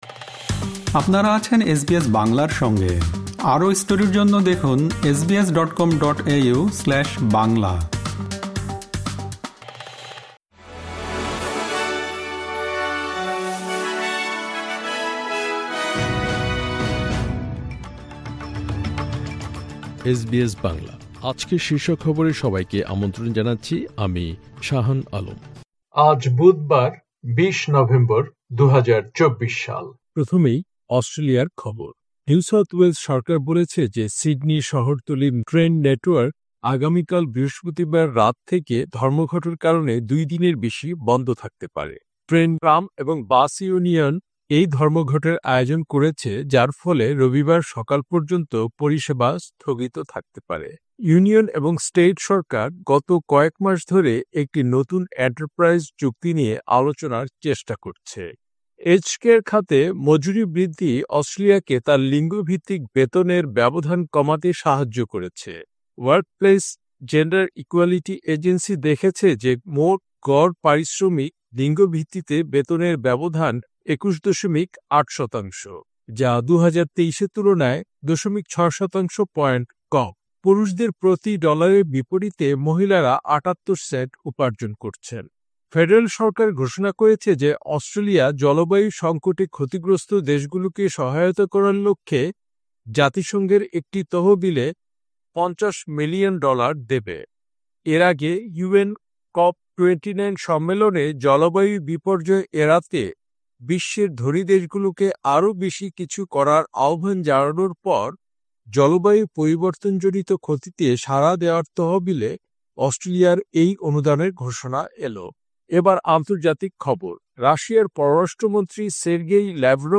এসবিএস বাংলা শীর্ষ খবর: ২০ নভেম্বর, ২০২৪